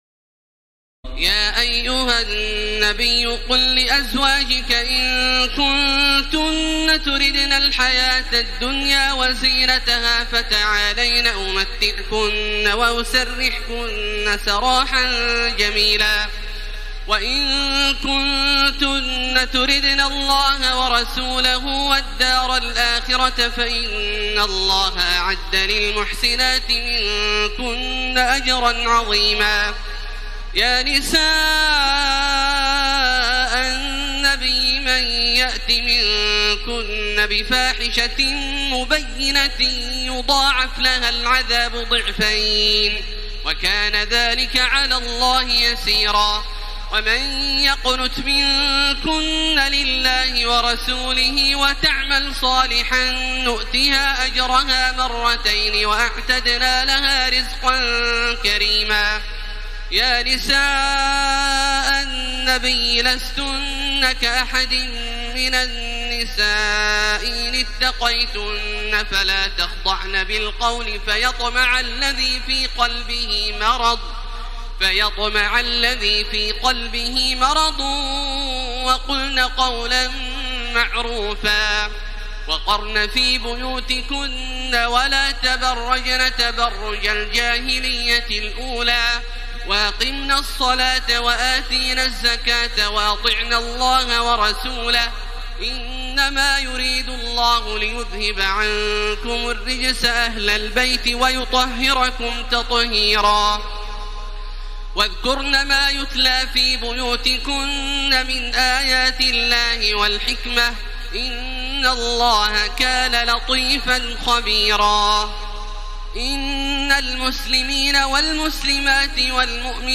تراويح ليلة 21 رمضان 1434هـ من سور الأحزاب (28-73) وسبأ (1-33) Taraweeh 21 st night Ramadan 1434H from Surah Al-Ahzaab and Saba > تراويح الحرم المكي عام 1434 🕋 > التراويح - تلاوات الحرمين